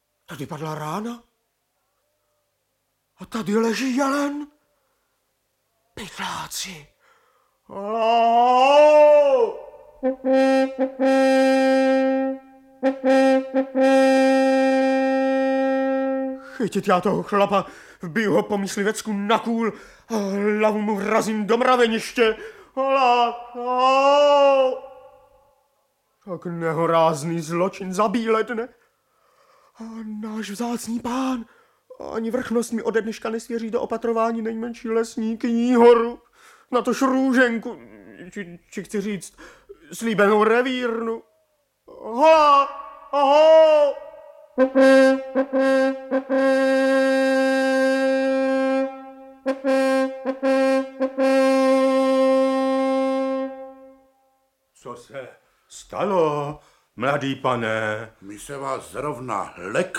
Audiobook
Audiobooks » Short Stories
Read: Otakar Brousek